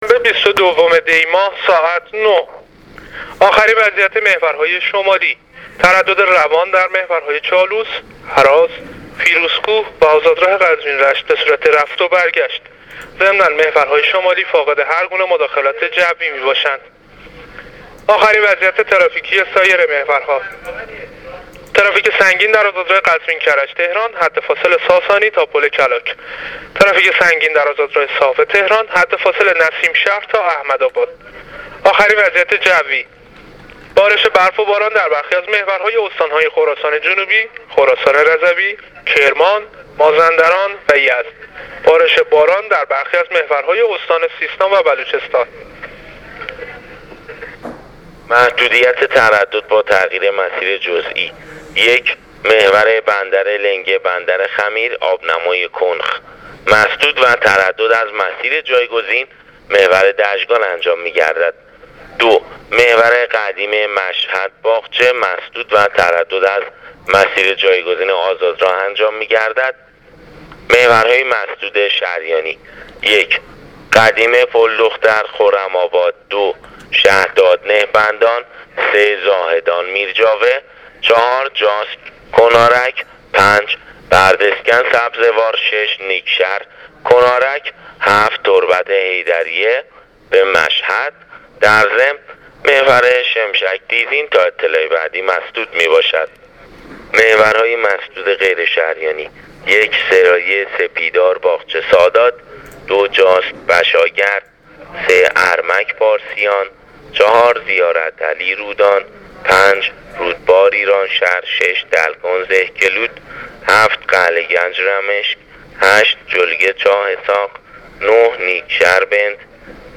گزارش رادیو اینترنتی از آخرین وضعیت‌ ترافیکی جاده‌ها تا ساعت ۹ بیست‌ودوم دی ۱۳۹۸: